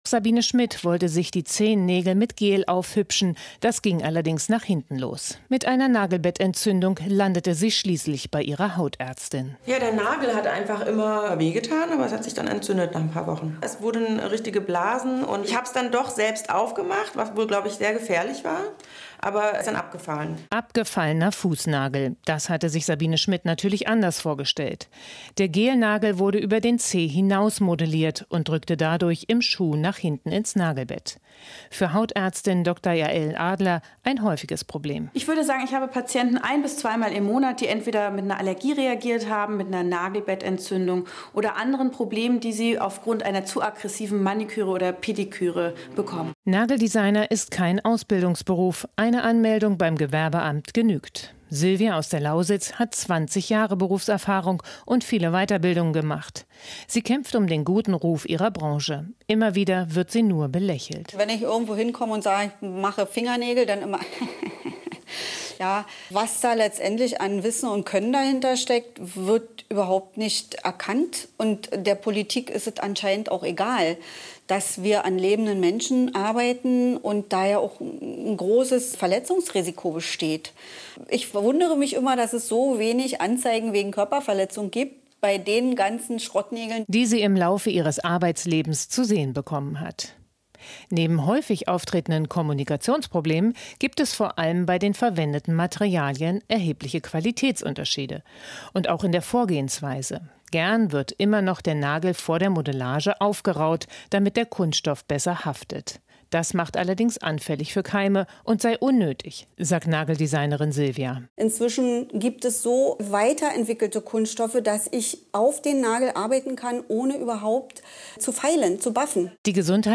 Inforadio Nachrichten, 02.10.2023, 06:00 Uhr - 02.10.2023